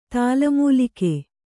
♪ tāla mūlike